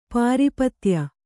♪ pāripatya